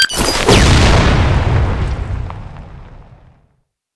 grenadethrow.wav